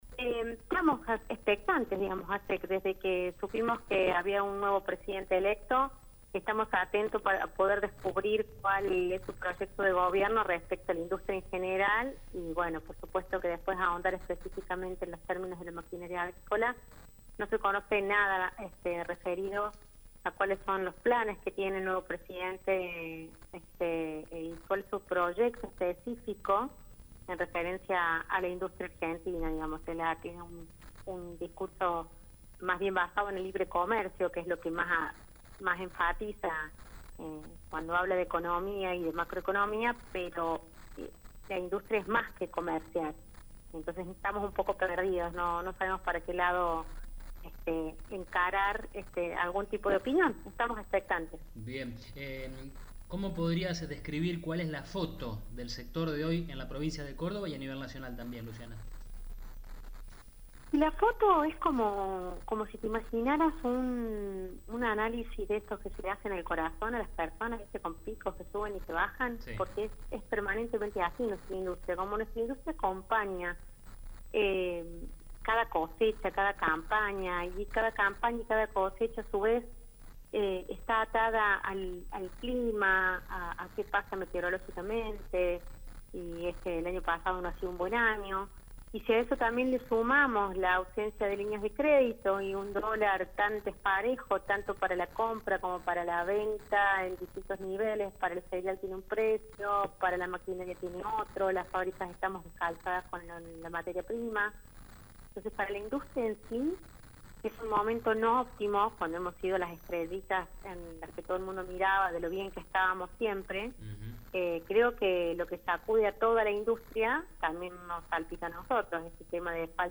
Se conmemora hoy 12 de diciembre el Día de la Maquinaria Agrícola Nacional. Una buena excusa para que El Campo Hoy entreviste a una de las referentes de esta industria de Córdoba y de todo el país.